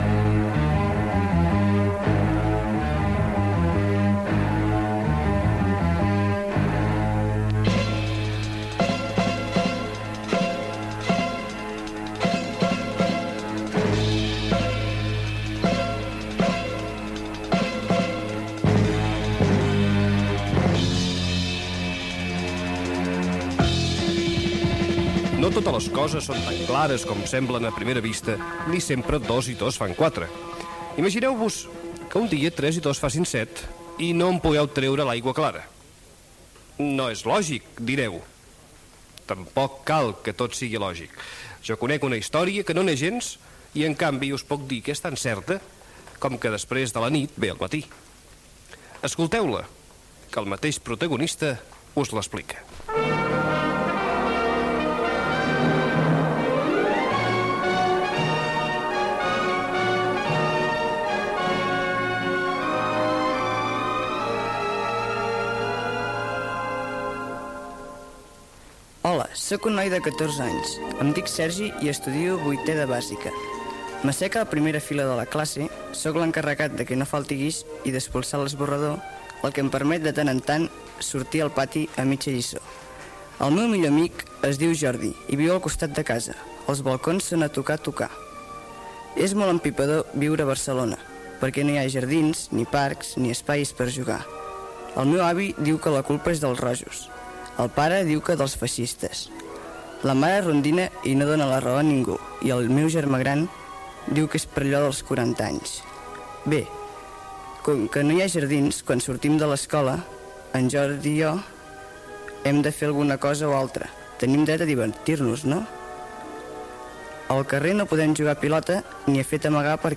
Infantil-juvenil
FM